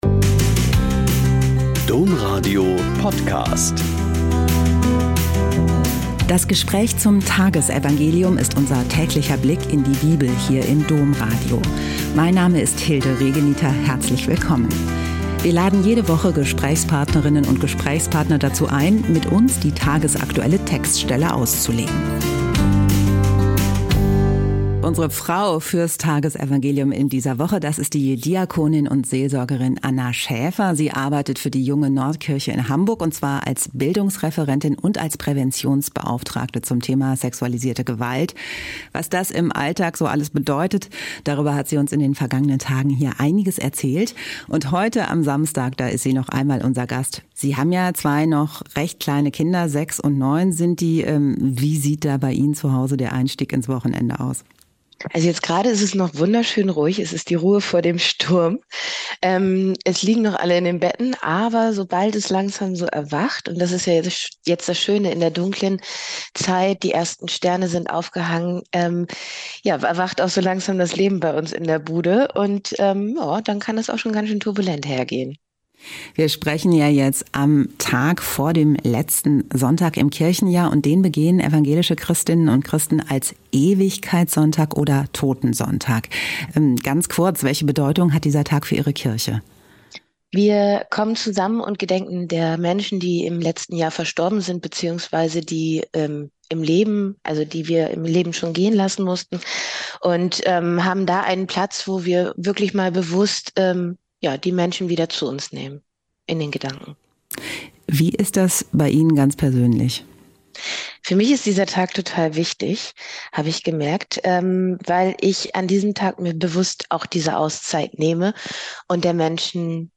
Mt 4,18-22 - Gespräch